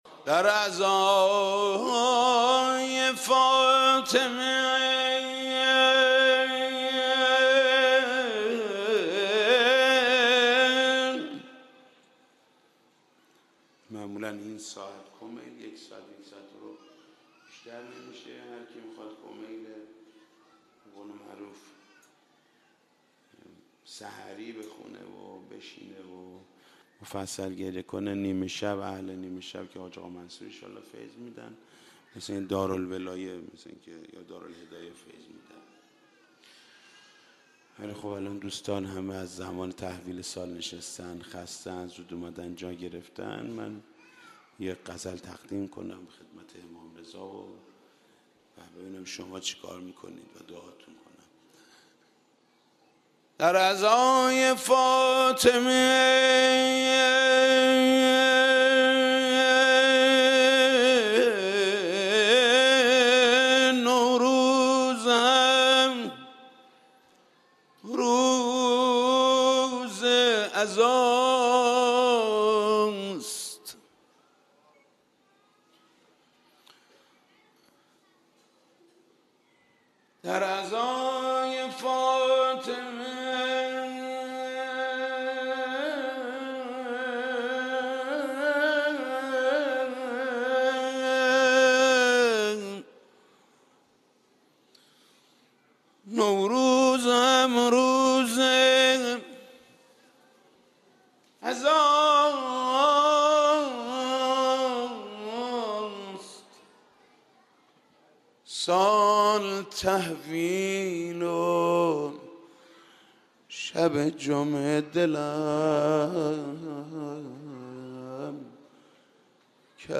[CENTER][COLOR=#051D4A][COLOR=#051D4A][CENTER][COLOR=#051D4A][COLOR=#051D4A][CENTER][COLOR=#008000][B]مراسم قرائت دعای کمیل در شب سال تحویل سال ۱۳۹۳ با مداحی حاج محمود کریمی در رواق امام خمینی (ره) حرم امام رضا (ع) به صورت صوتی آماده دانلود میباشد.